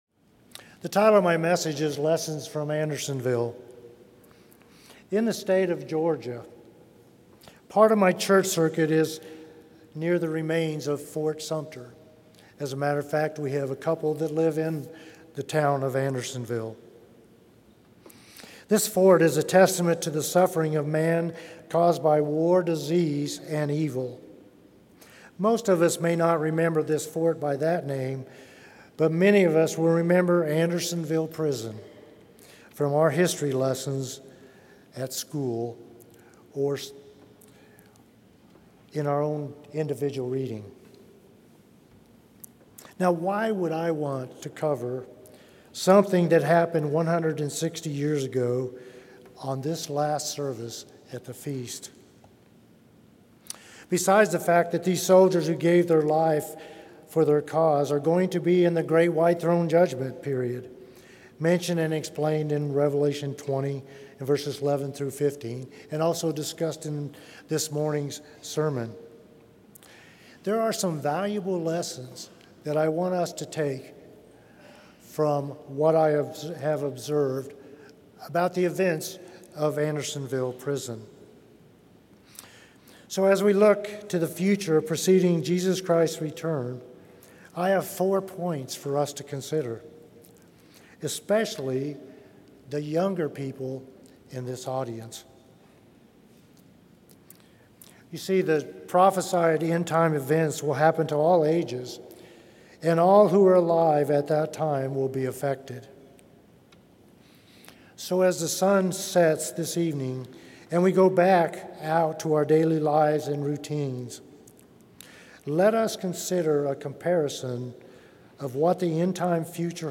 This sermon was given at the Gatlinburg, Tennessee 2024 Feast site.